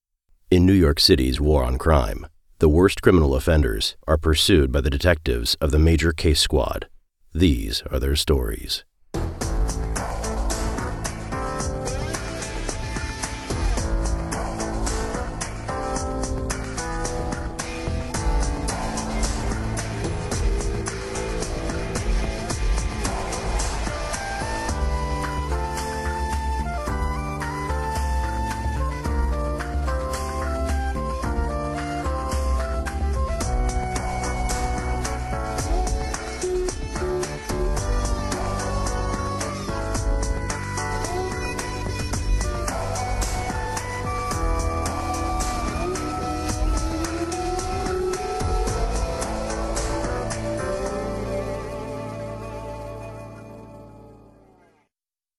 Male
Impersonations
0430CI_Intro_with_Song.mp3